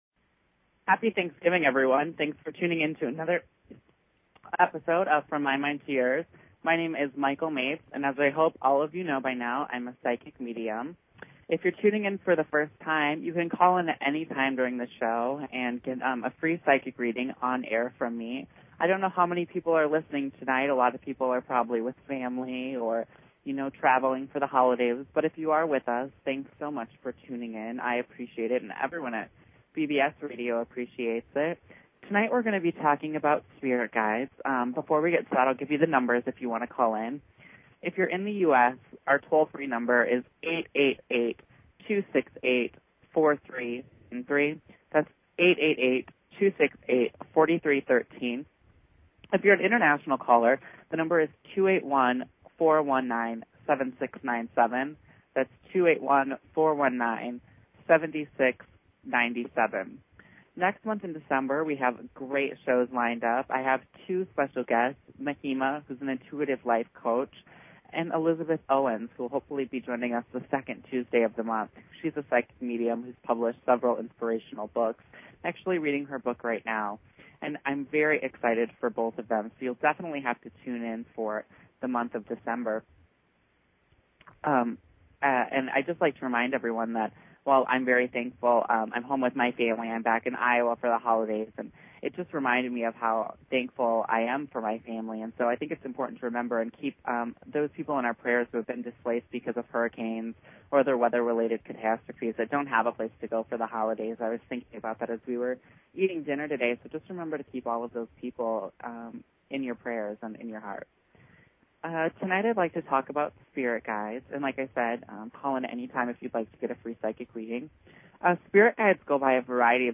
Talk Show Episode, Audio Podcast, From_My_Mind_To_Yours and Courtesy of BBS Radio on , show guests , about , categorized as